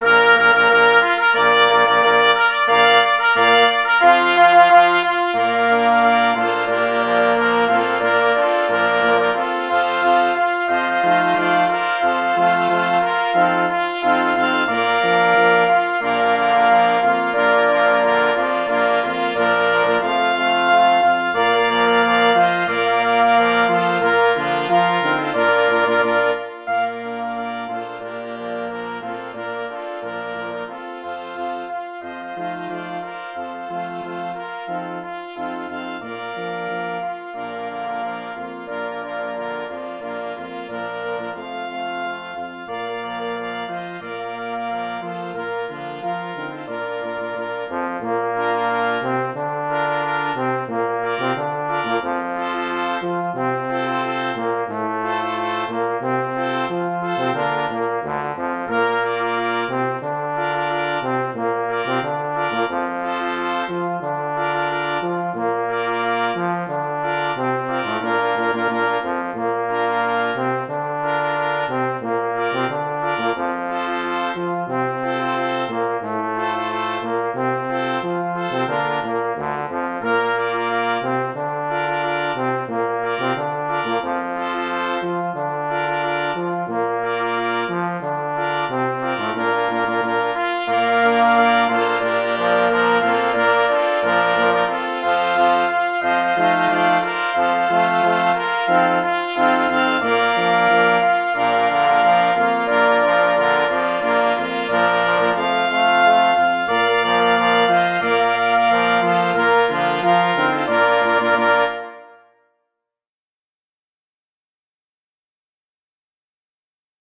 Witaj w Spale. Marsz-Fanfara - Naczelna Rada Łowiecka
Witaj w Spale – 2 plesy 2 parforsy i kwintet dęty blaszany [ ZESPÓŁ ROGÓW MYŚLIWSKICH ] | PDF
Witaj w Spale – na 2 plesy + 2 parforsy + kwintet dęty blaszany | MP3